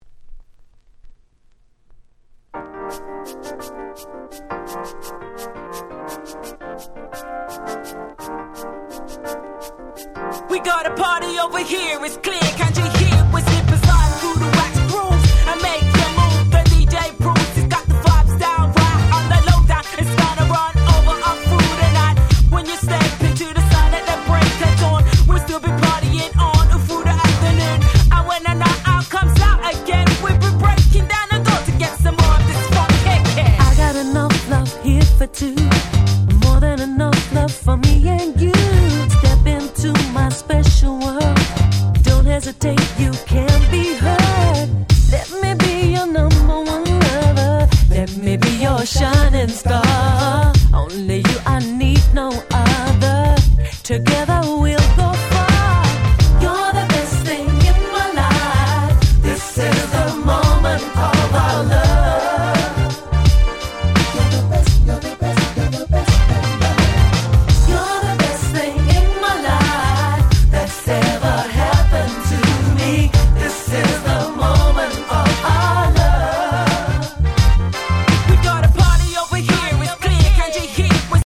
97' Super Nice UK R&B !!